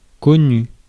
Ääntäminen
IPA: /kɔ.ny/